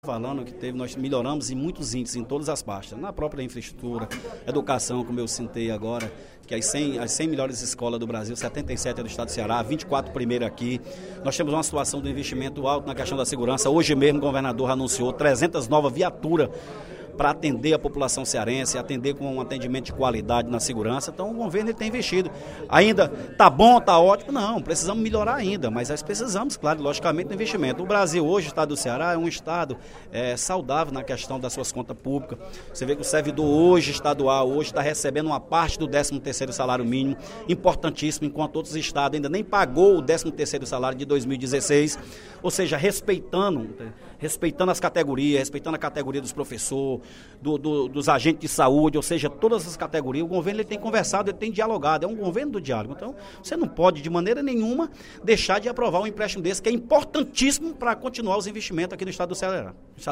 O deputado Tomaz Holanda (PPS) defendeu, durante o primeiro expediente da sessão plenária desta quinta-feira (06/07), a aprovação de empréstimo do Governo do Estado junto ao Banco do Brasil, referente ao projeto de Amortização da Dívida Pública Estadual no Biênio de 2017/ 2018. De acordo com o parlamentar, a matéria é “importantíssima e garante a capacidade de investimentos do Estado”.